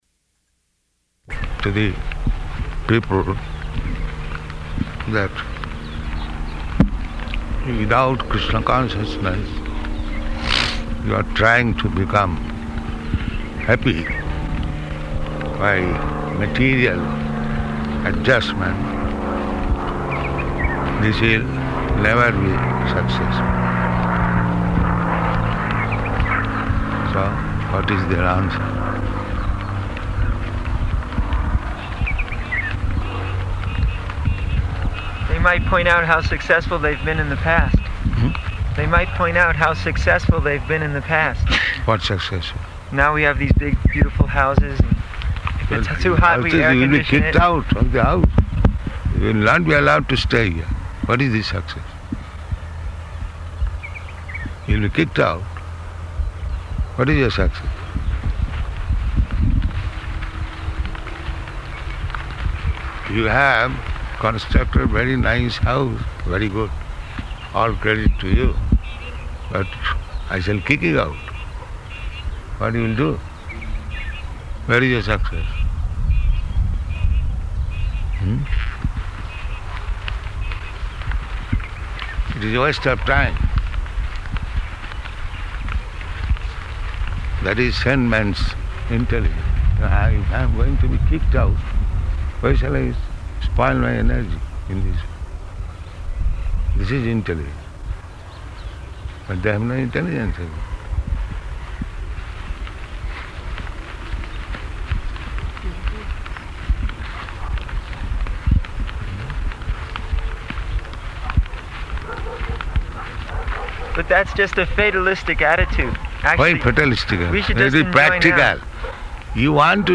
Type: Walk
Location: Johannesburg